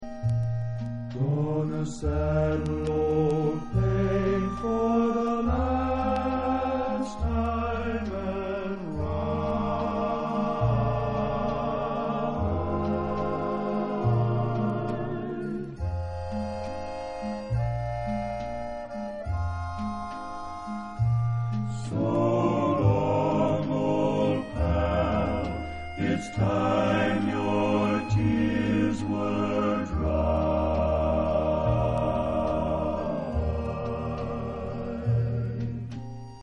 stereo